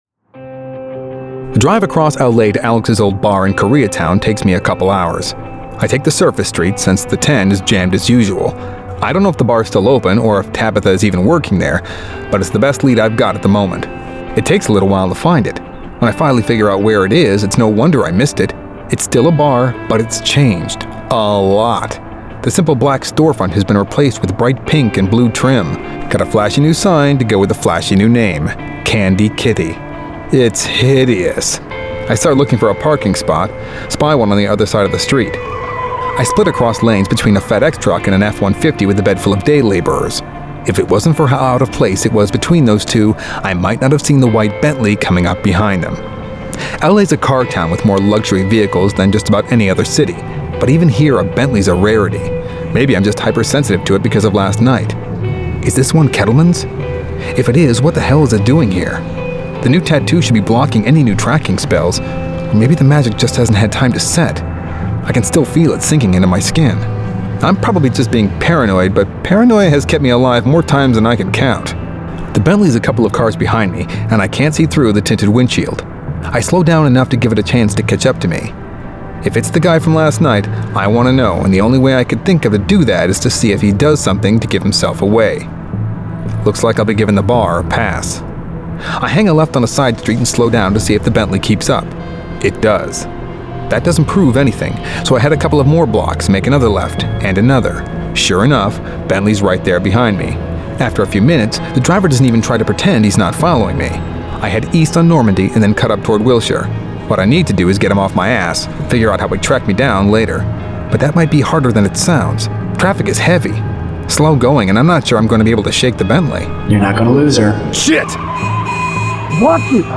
Eric Carter 2: Broken Souls [Dramatized Adaptation]